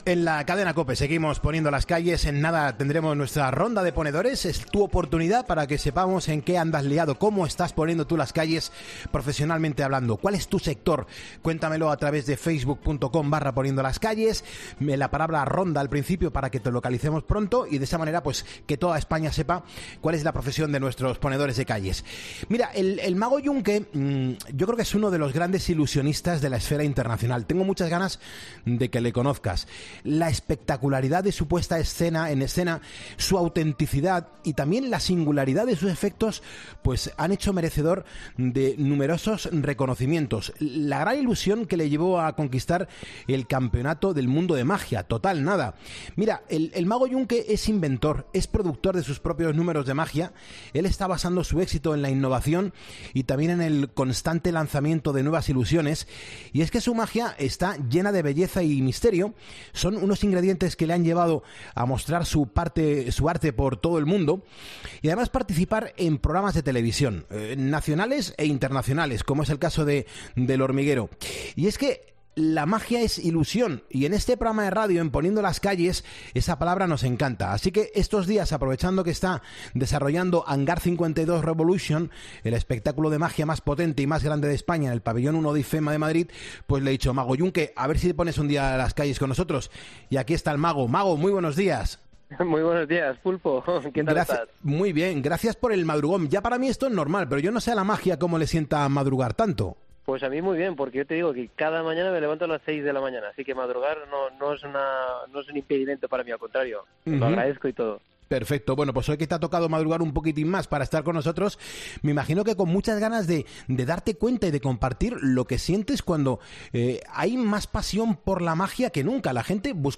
El reconocido como mejor mago del mundo se pasa por el estudio de Poniendo las Calles para hablarnos de magia y de sus nuevos proyectos